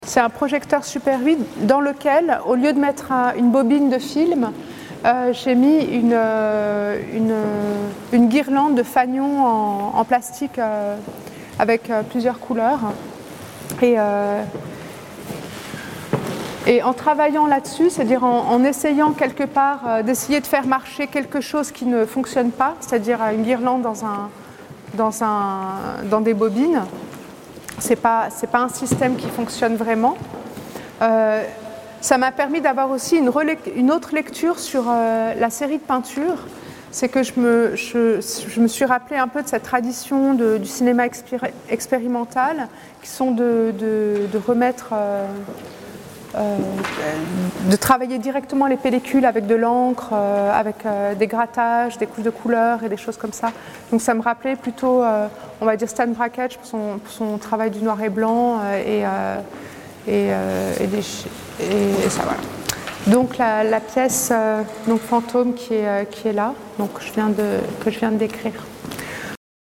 Le macLYON a proposé à Latifa Echakhch de parler de chacune des œuvres présentes dans l'exposition Laps, et a intégré ces séquences sonores à ses cartels.